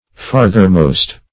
Farthermost \Far"ther*most`\, a.